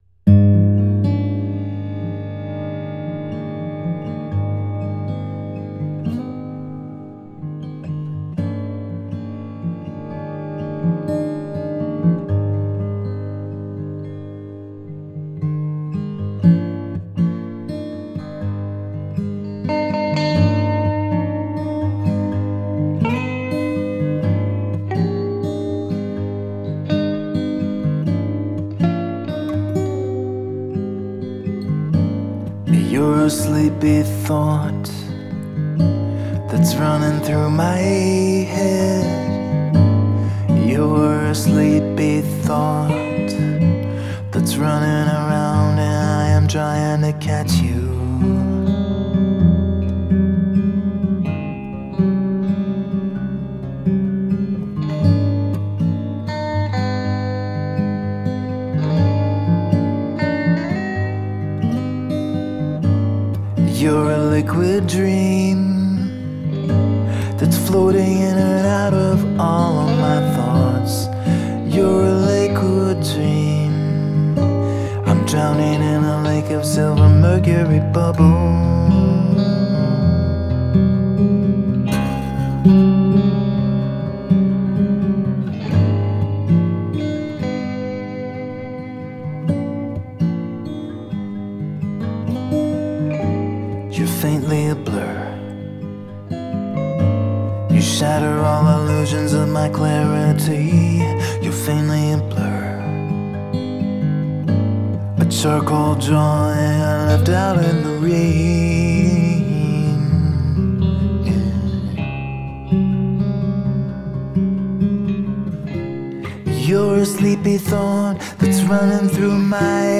Genre: Acoustic